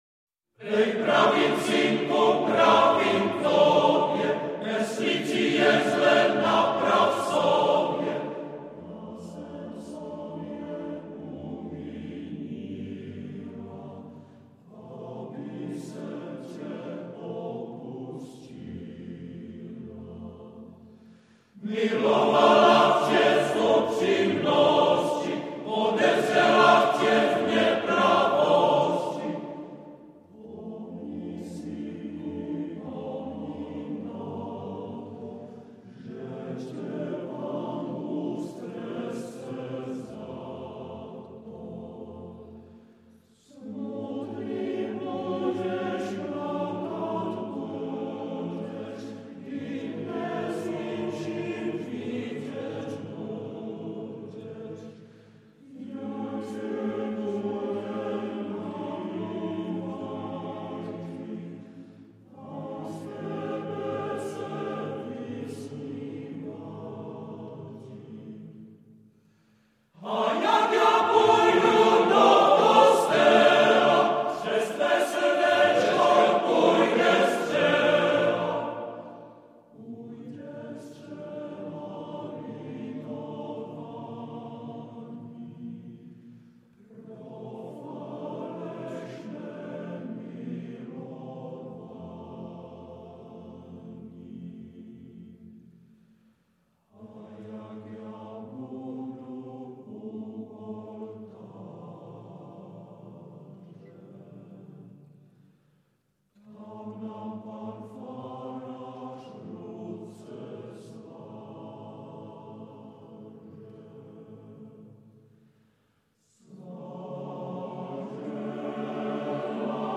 FF:VH_15b Collegium male choir